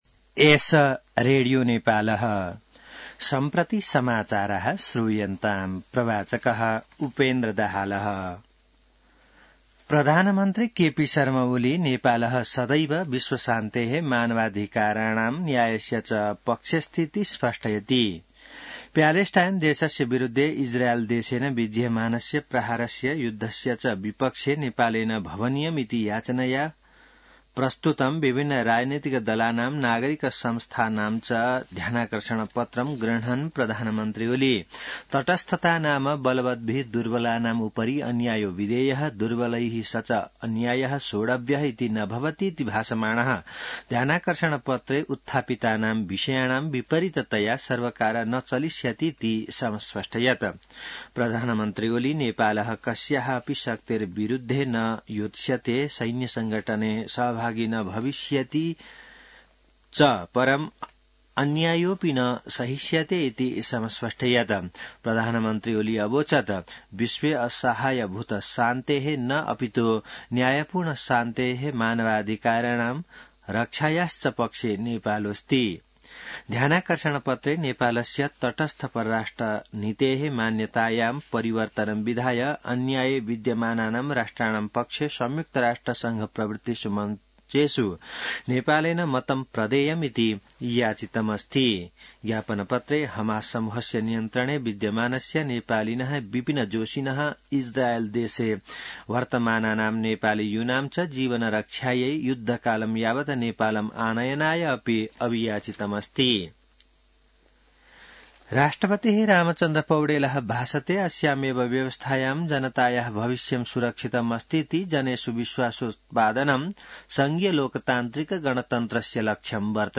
संस्कृत समाचार : ७ मंसिर , २०८१